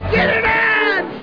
8d82b5_cars_mater_git-r-done_sound_effect.mp3